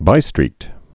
(bīstrēt)